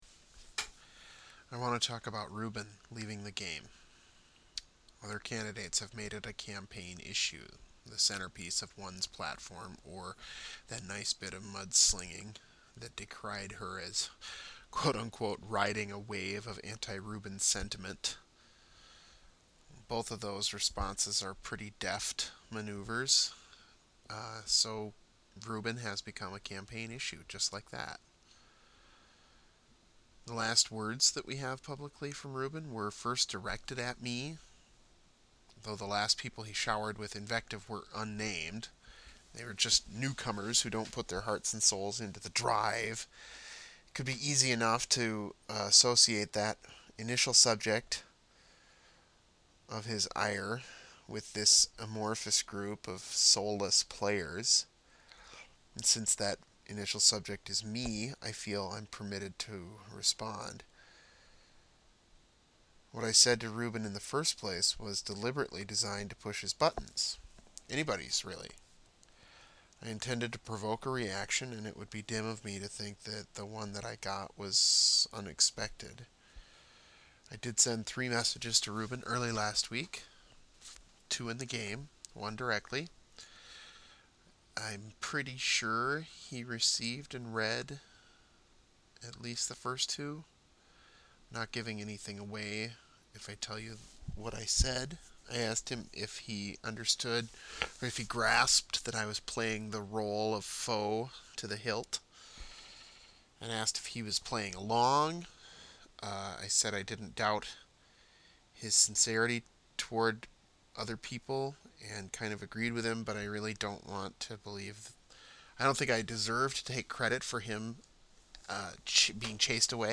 I have prepared a lengthy address on this critical matter.